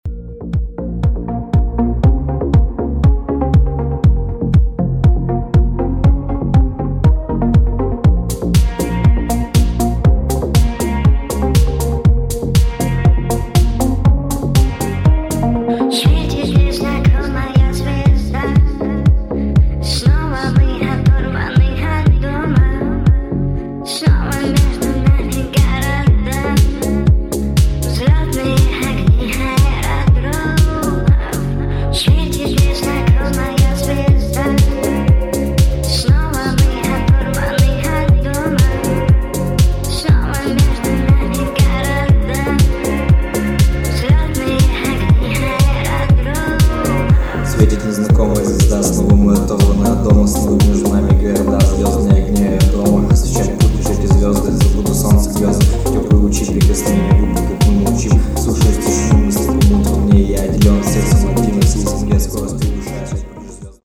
• Качество: 320, Stereo
remix
женский голос